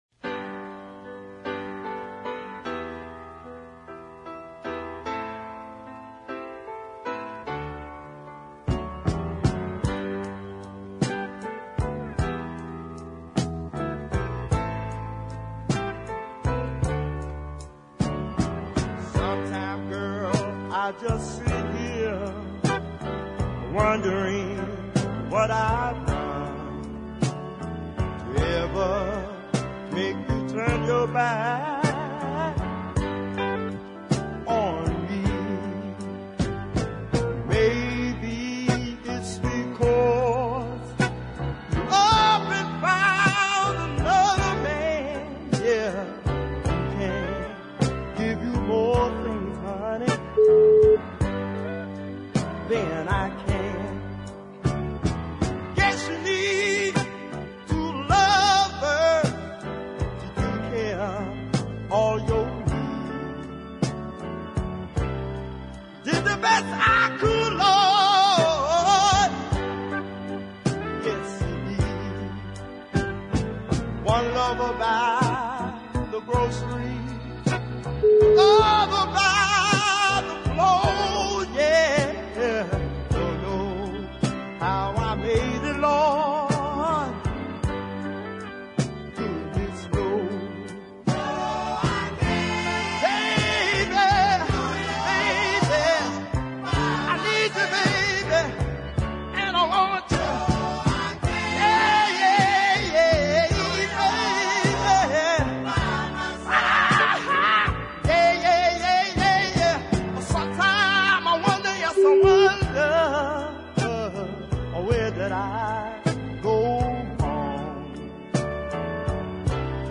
really fine deep soul ballad